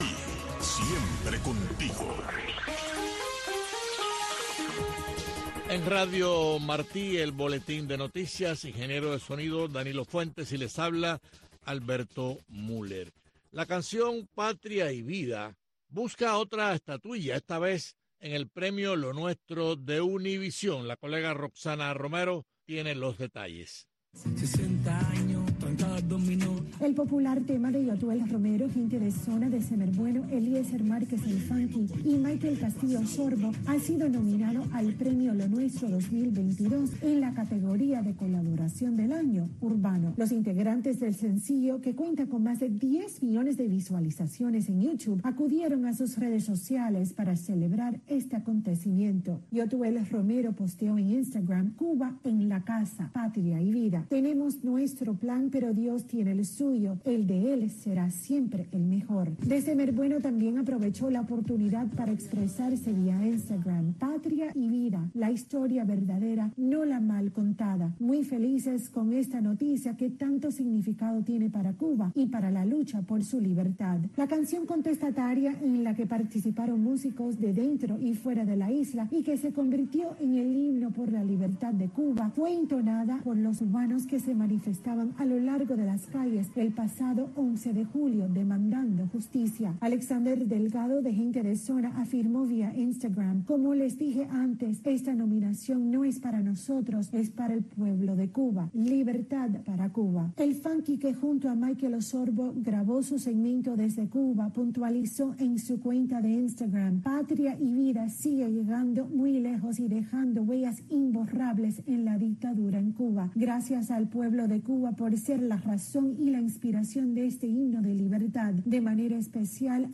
Entrevistas e informaciones con las voces de los protagonistas desde Cuba.